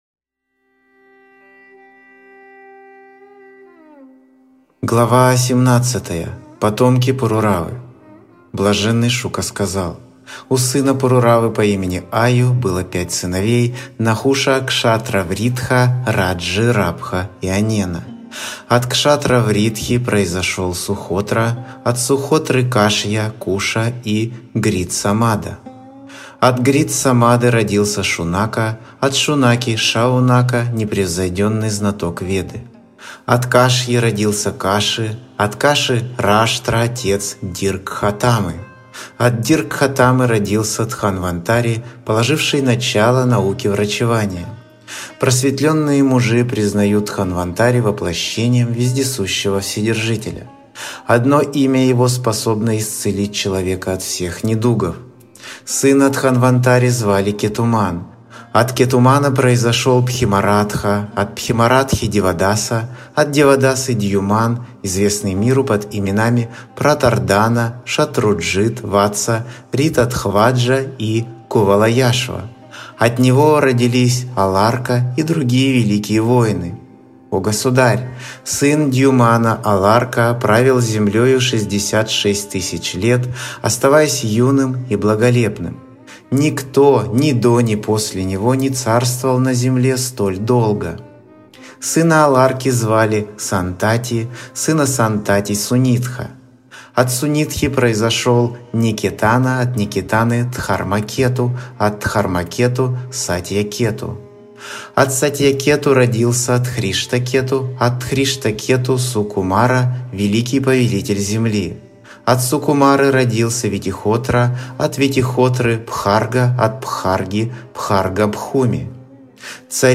Аудиокнига Шримад Бхагаватам 9